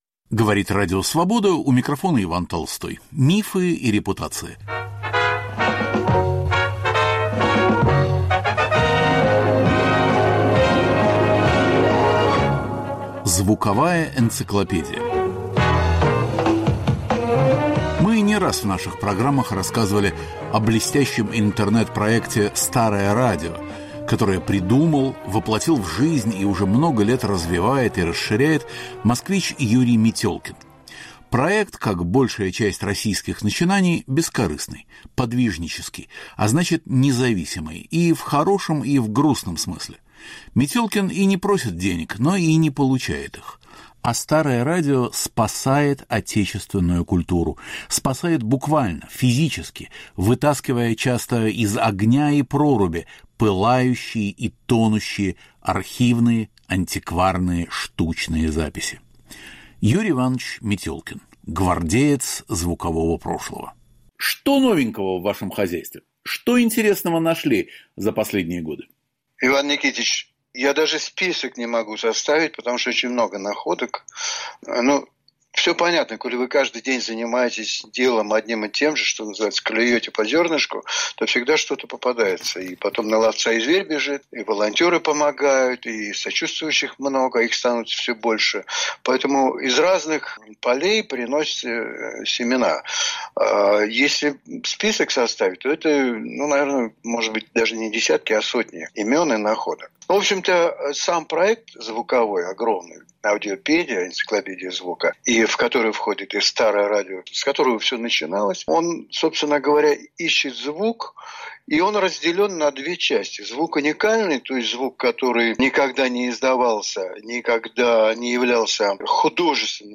мы беседуем о его находках последнего времени.